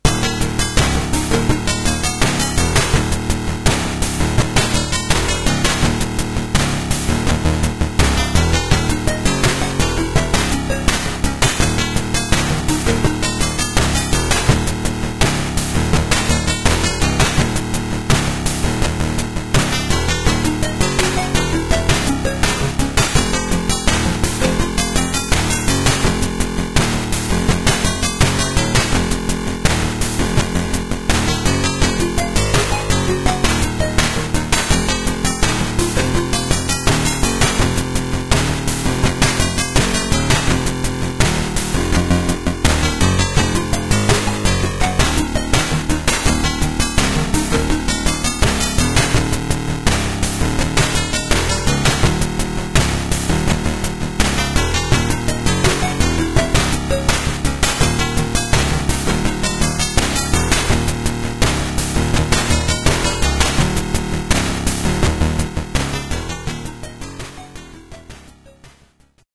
Main menu tune in OGG format (1.8 Mb)